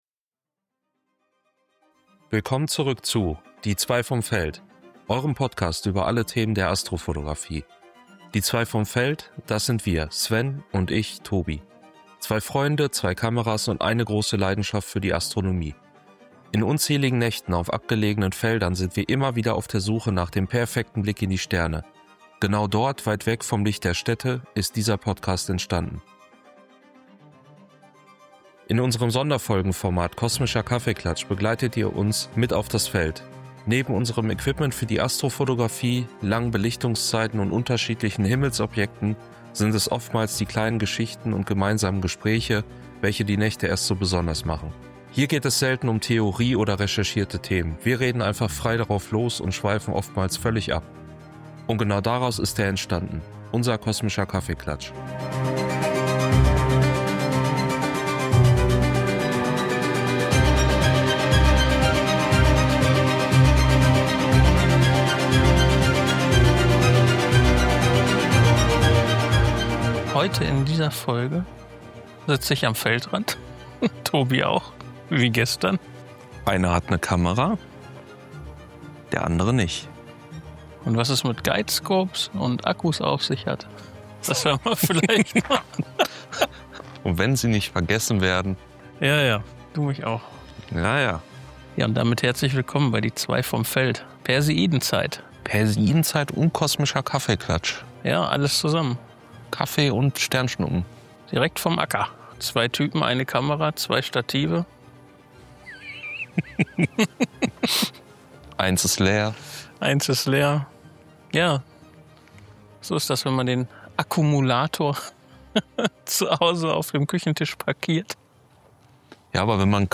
In unserem Sonderfolgenformat Kosmischer Kaffeeklatsch begleitet ihr uns mit auf das Feld.
Hier geht es selten um Theorie oder recherchierte Themen, wir reden einfach frei drauf los und schweifen oftmals völlig ab.